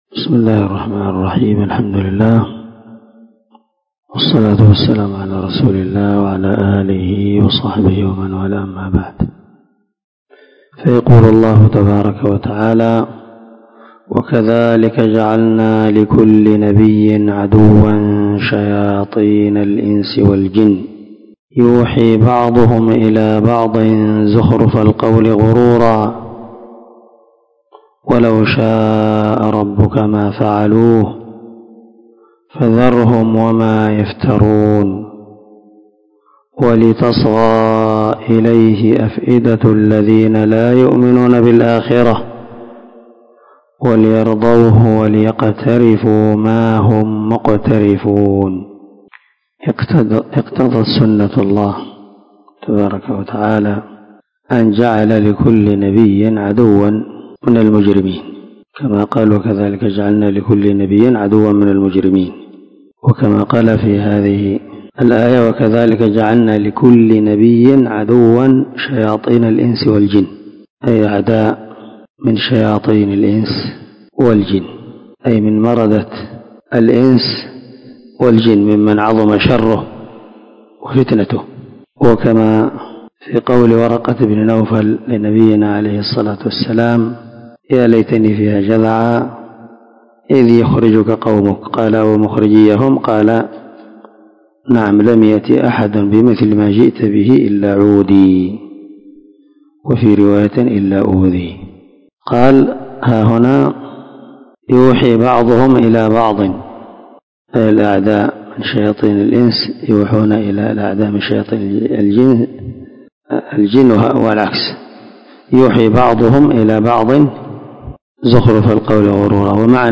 428الدرس 36 تفسير آية ( 112 - 113 ) من سورة الأنعام من تفسير القران الكريم مع قراءة لتفسير السعدي